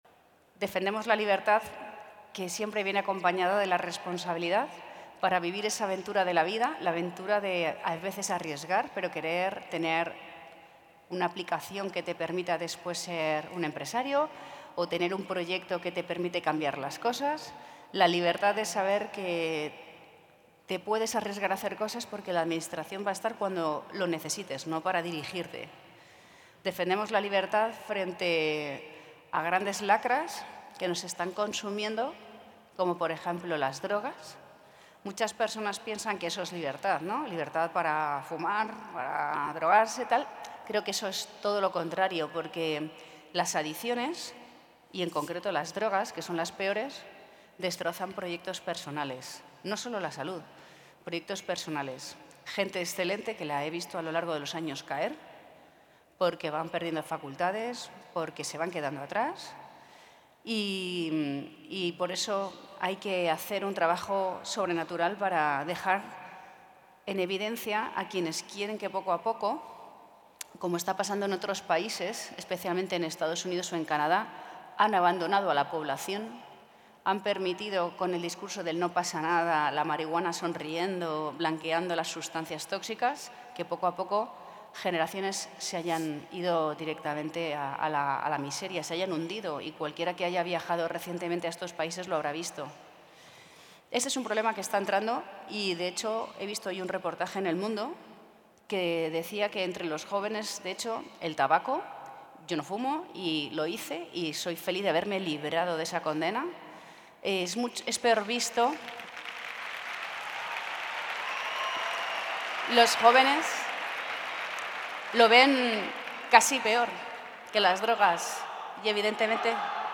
13/12/2023 La presidenta de la Comunidad de Madrid, Isabel Díaz Ayuso, ha clausurado hoy la 17a edición del congreso de la Fundación Lo Que De Verdad Importa, que ha reunido en el Palacio de Vistalegre de la capital a 6.000 jóvenes.